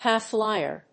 /ˈhaɪˌflaɪɝ(米国英語), ˈhaɪˌflaɪɜ:(英国英語)/
アクセントhígh‐flìer